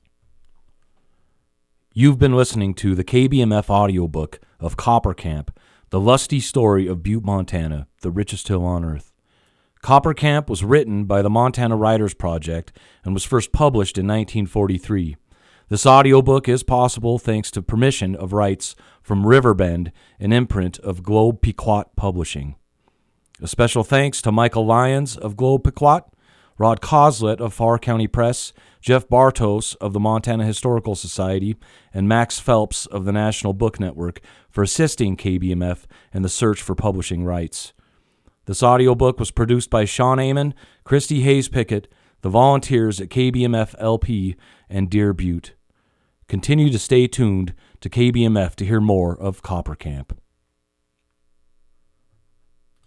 Outro Spoken.mp3